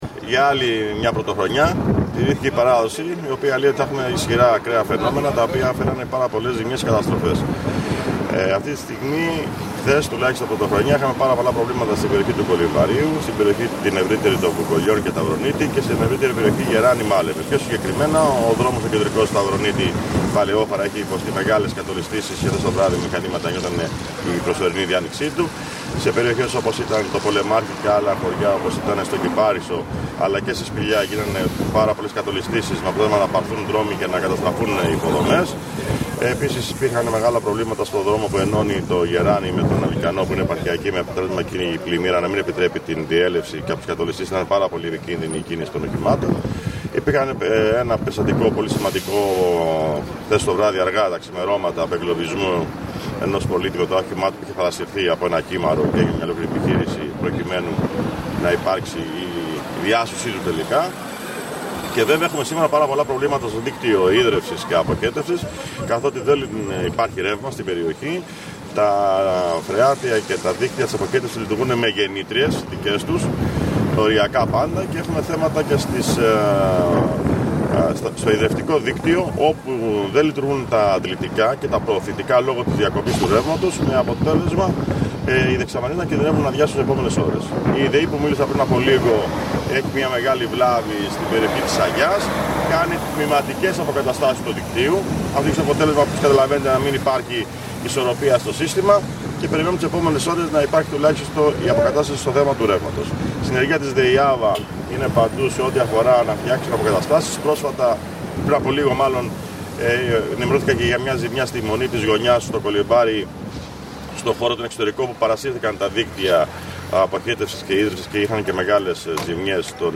Ακούστε τις δηλώσεις του Δημάρχου Πλατανιά, Γιάννη Μαλανδράκη:
ΜΑΛΑΝΔΡΑΚΗΣ-ΓΙΑΝΝΗΣ-Δήμαρχος-Πλατανιά.mp3